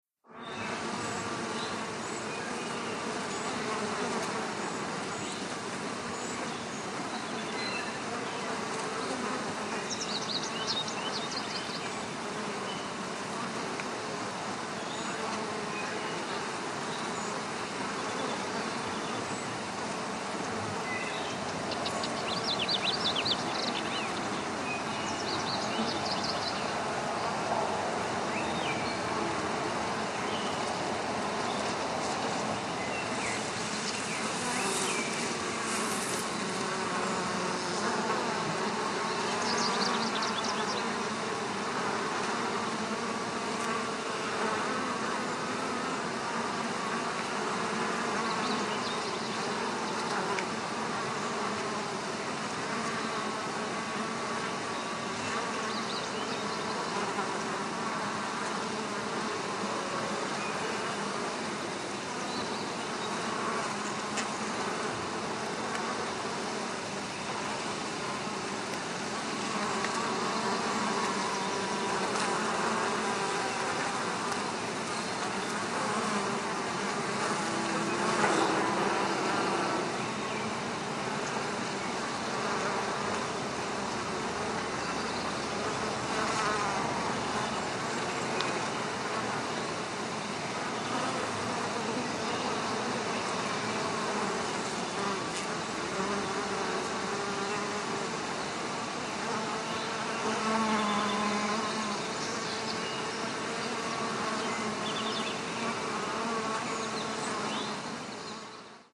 BeesManySummerDay PE660601
ANIMAL BEES: EXT: Many on a summer day, low level constant wind background, distant bird calls.